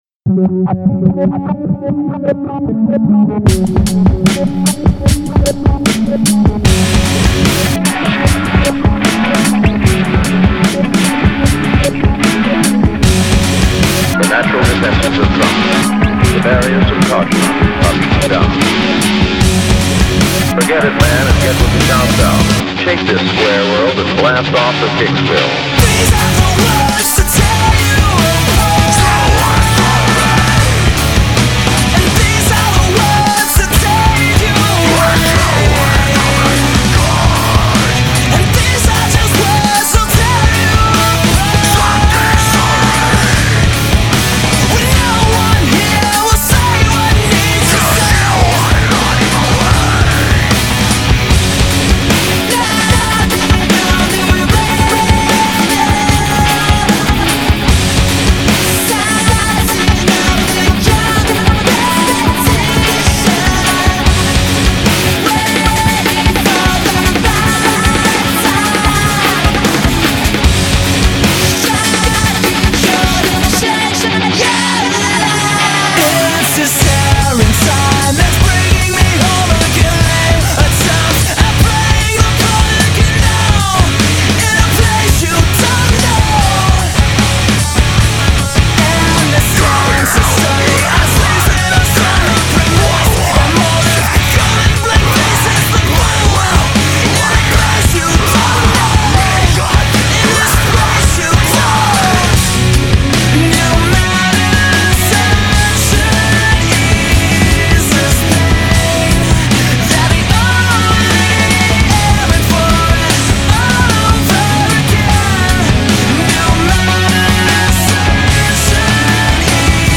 Категория: Rock, Alternative